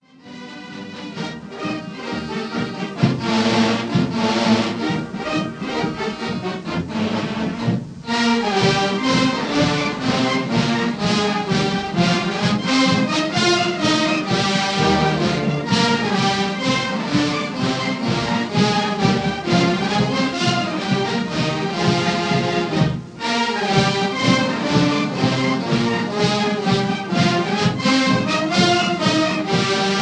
recorded in Rushmoor Arena
Aldershot June 1934